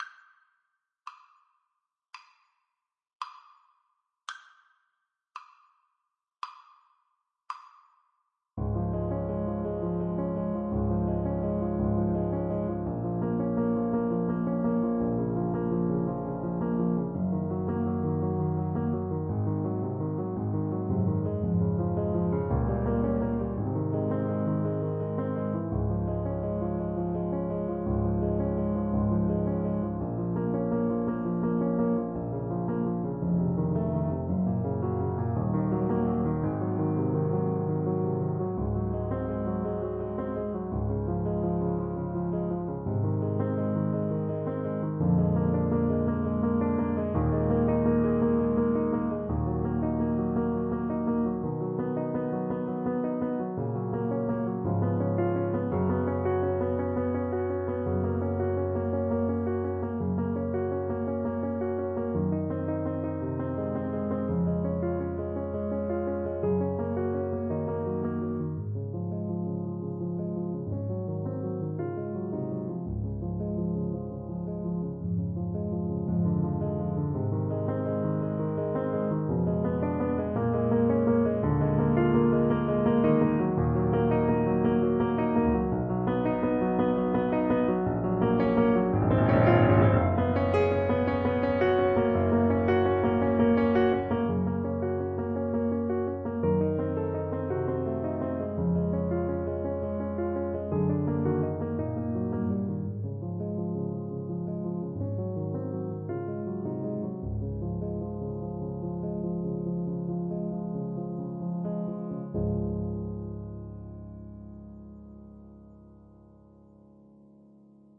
Alto Saxophone
4/4 (View more 4/4 Music)
Andante = c. 76
Classical (View more Classical Saxophone Music)